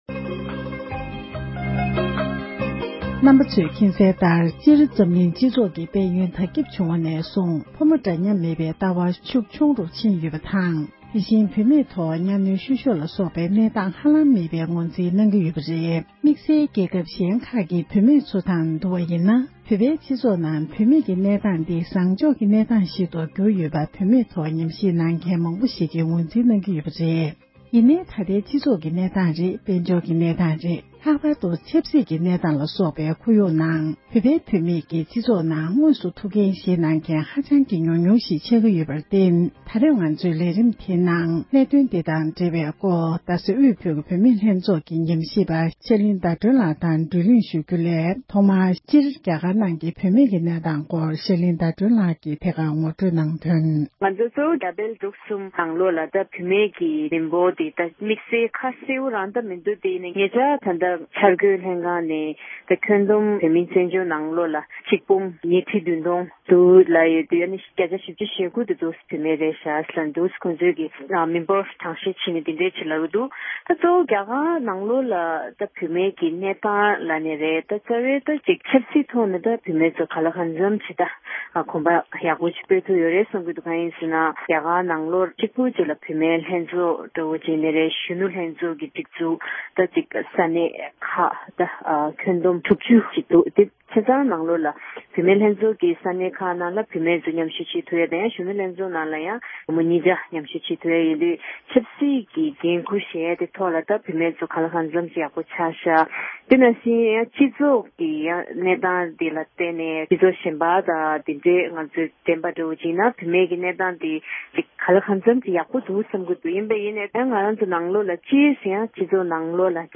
བཙན་བྱོལ་ནང་དུ་ཡོད་པའི་བུད་མེད་རྣམས་ཀྱི་ཐོབ་ཐང་སྐོར་འབྲེལ་ཡོད་མི་སྣར་གནས་འདྲི་ཞུས་པ།